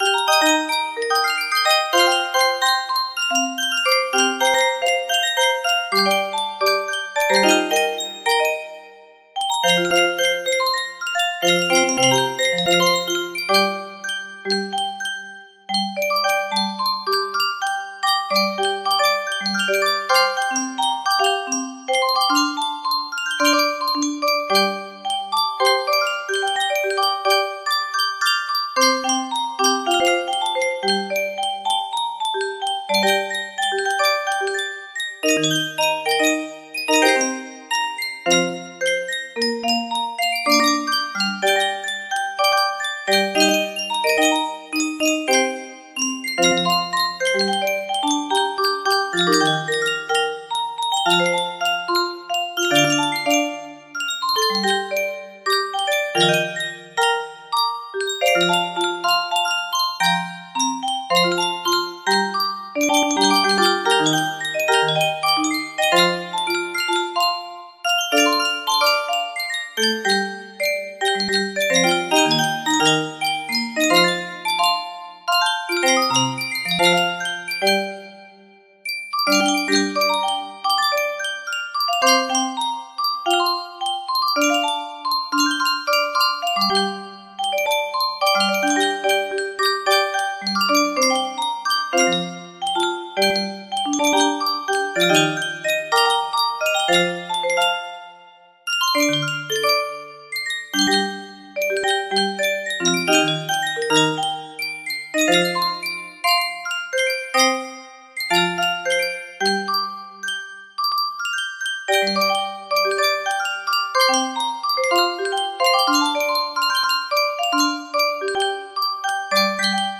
Her Eyes Are Like The Stars Reprise music box melody
Full range 60